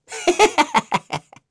Neraxis-Vox_Happy2.wav